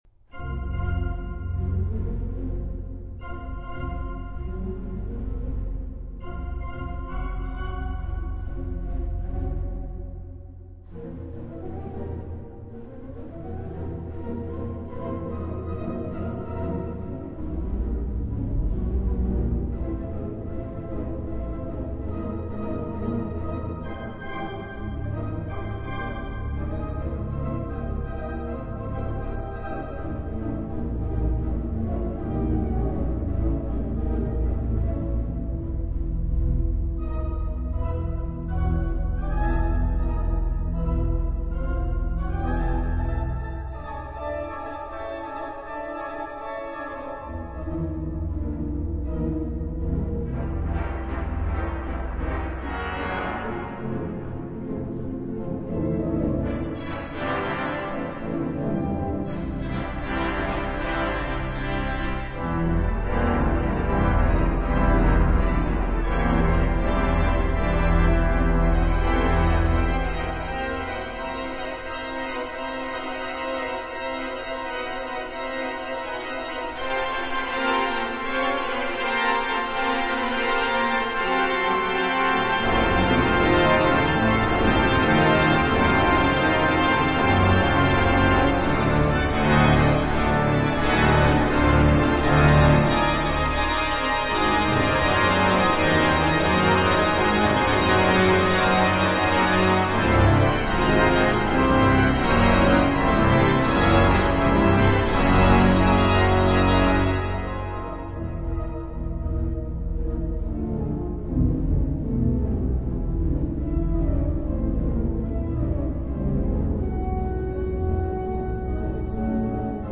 Vier Skizzen für den Pedalflügel op. 58 Improvisation